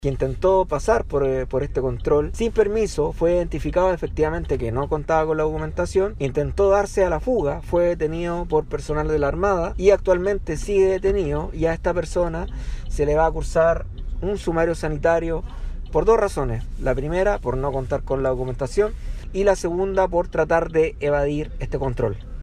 En tanto, el seremi de salud, Francisco Álvarez, indicó que ya hay una persona detenida por intentar pasar el cordón sanitario en Casablanca en el sector de Veramonte.